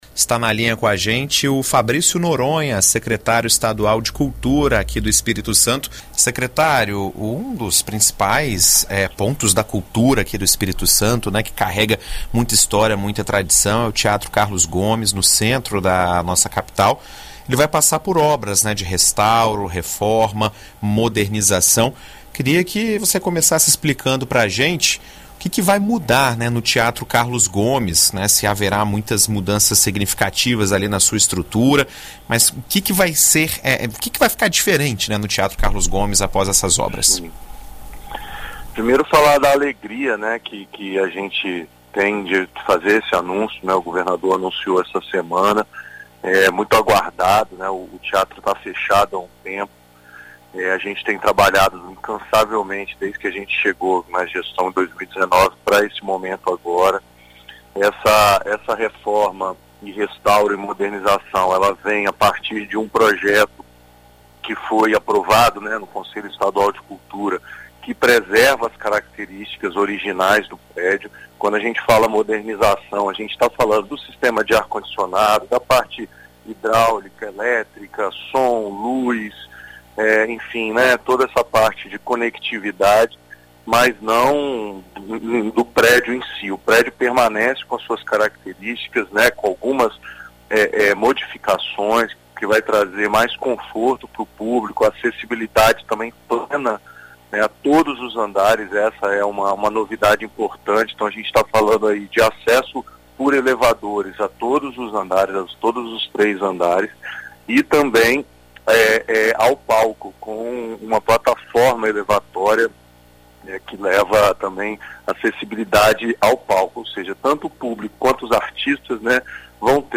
Em entrevista à BandNews FM Espírito Santo , o Secretário Estadual de Cultura, Fabrício Noronha, comenta sobre a modernização do espaço e como funcionará as atividades culturais.